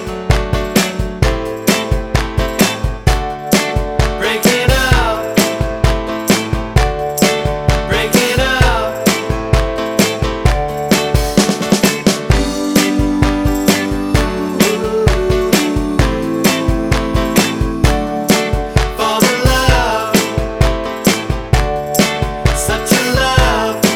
Without Bass Guitar Pop (1970s) 3:13 Buy £1.50